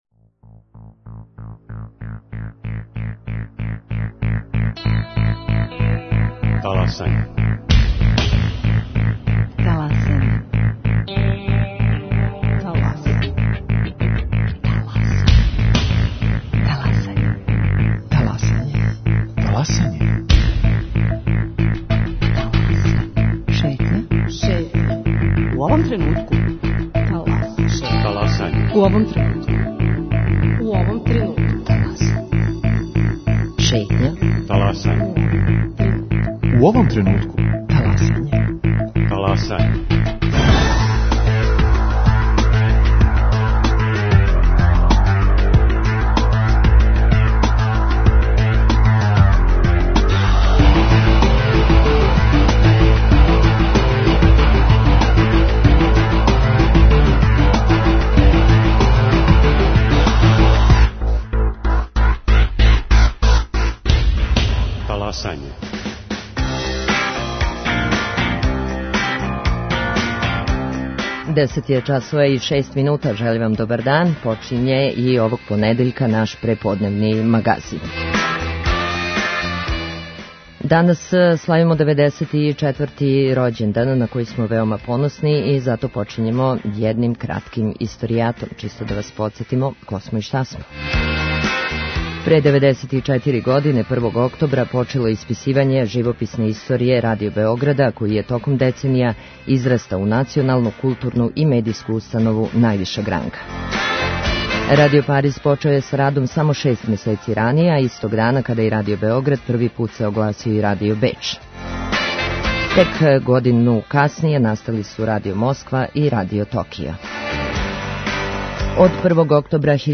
У другом сату емисије пружамо вам прилику да "таласате" заједно са нама. Ви причате, сугеришете, коментаришете - ми слушамо!